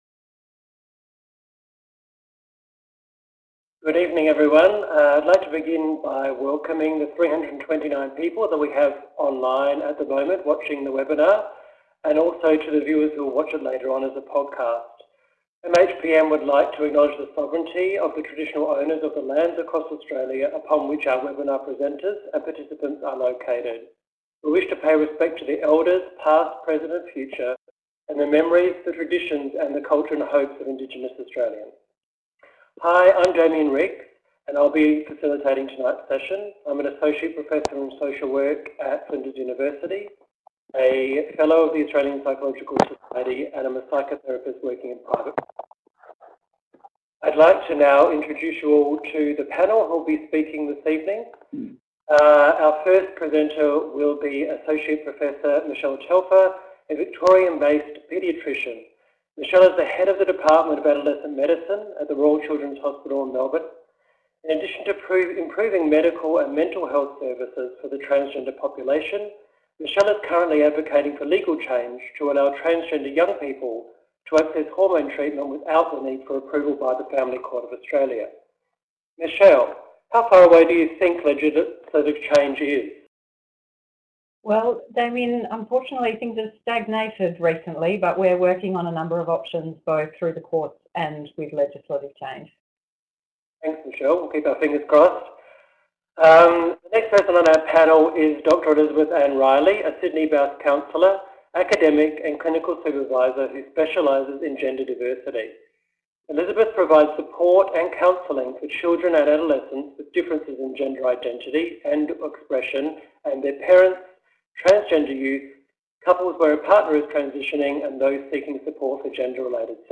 Join our interdisciplinary panel of experts for a discussion about how can we best support the mental health of young people who experience gender dysphoria.